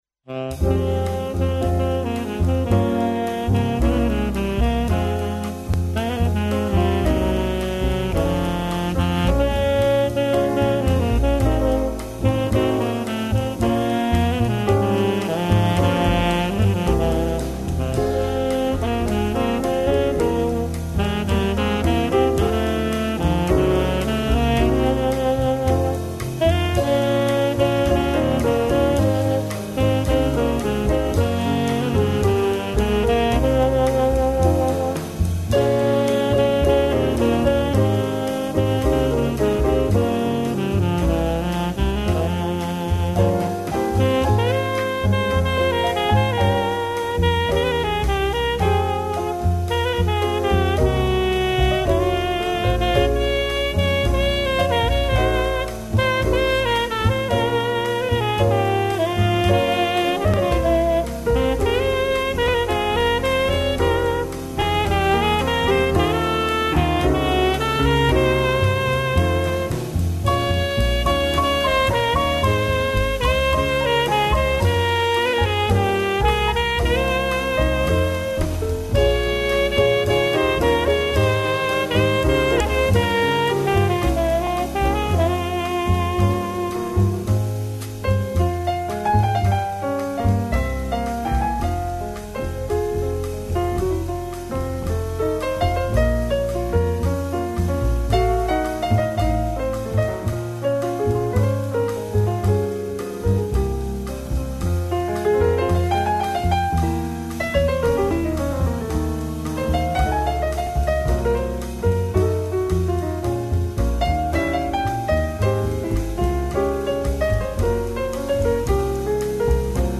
blues band
тромбон, гитара
бас